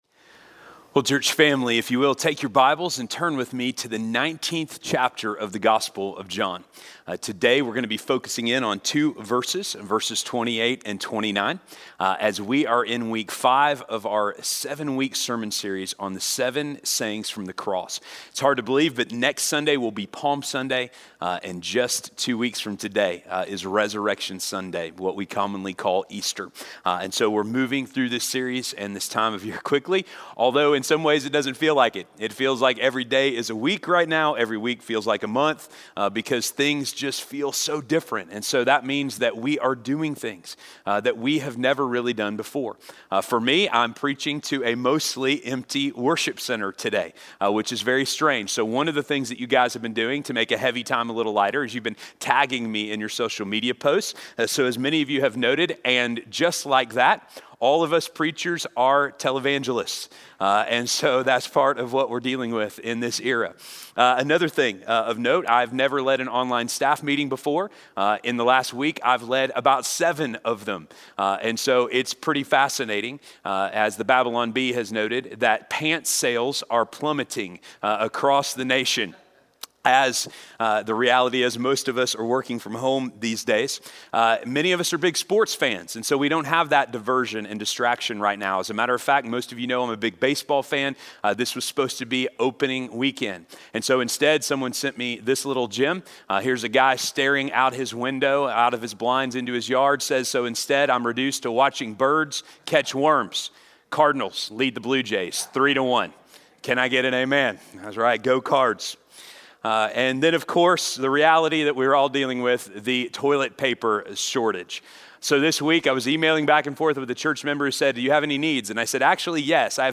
I Thirst - Sermon - Station Hill